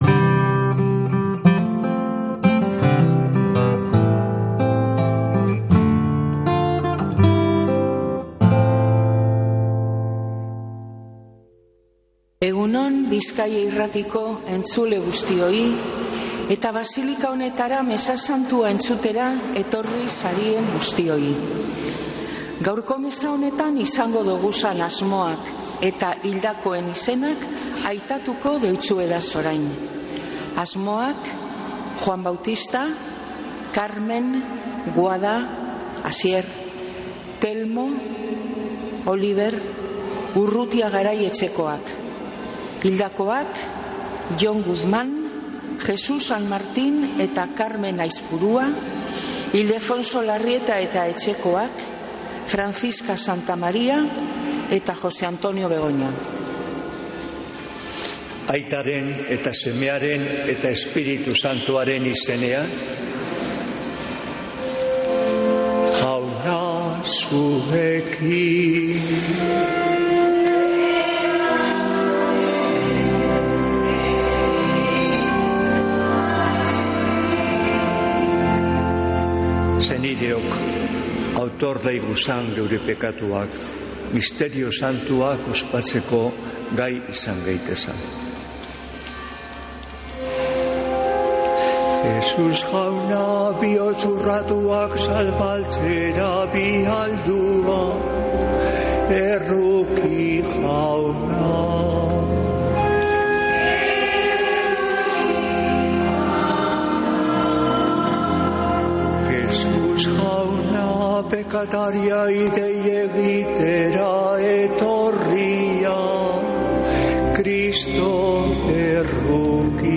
Mezea (25-09-05)